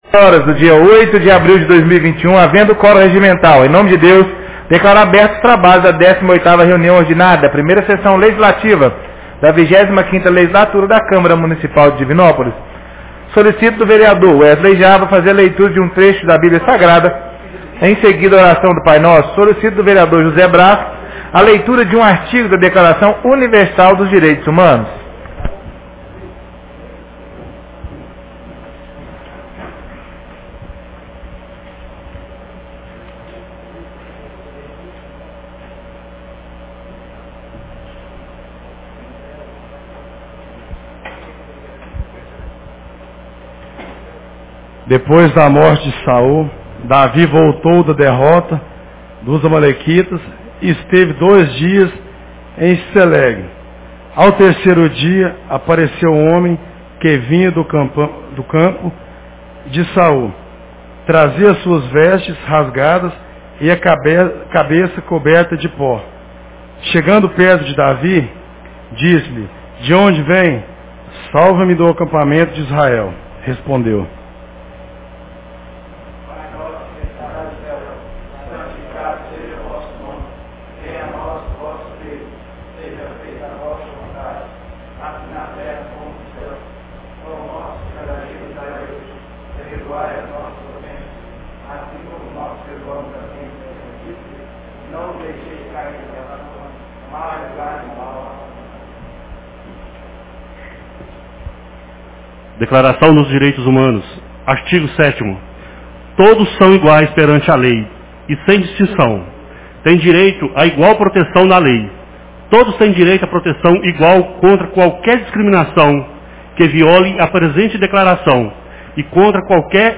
Reunião Ordinária 18 de 08 de abril 2021